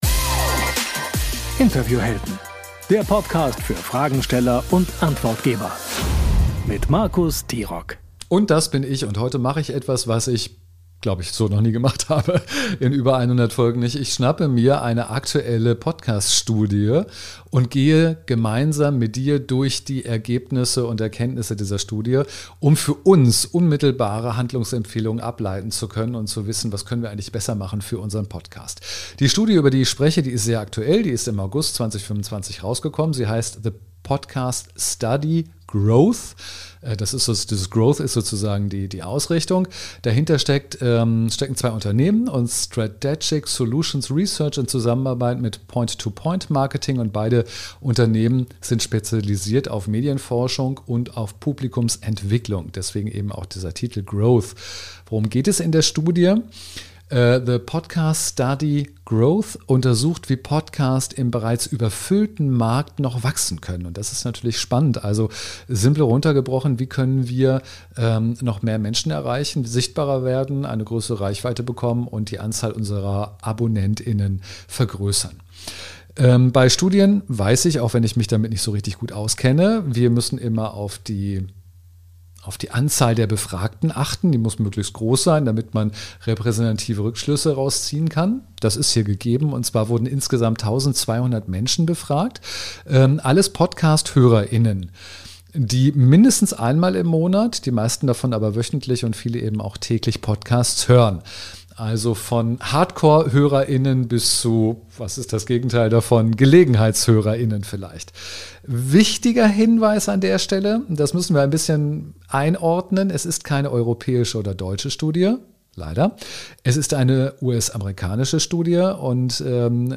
Beschreibung vor 6 Monaten In dieser Soloepisode tauche ich tief in die **Podcast Study Growth** ein – und zeige dir, was die Ergebnisse wirklich bedeuten. Statt Zahlenkolonnen bekommst du von mir **fünf konkrete Handlungsempfehlungen**, die du direkt auf deinen eigenen Podcast anwenden kannst.